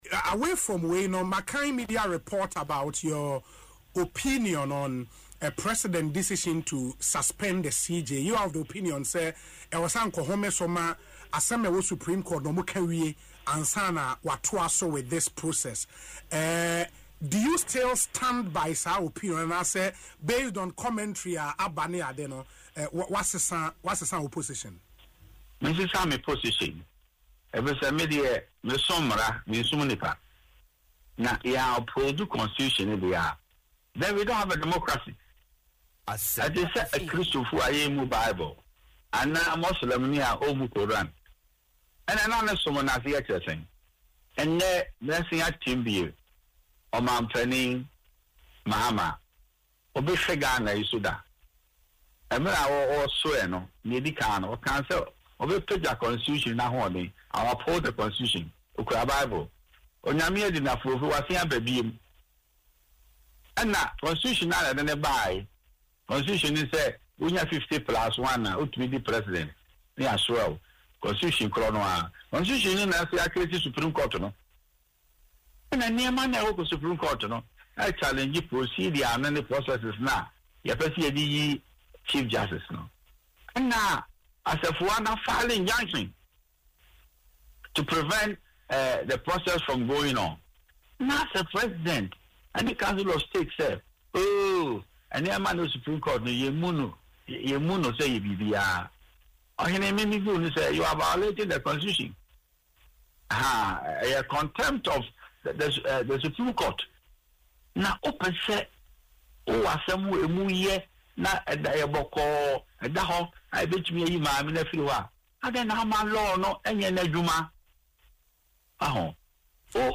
In an interview on Asempa FM’s Ekosii Sen, Mr. Atta Akyea, who is also a lawyer, criticised the suspension of the Chief Justice.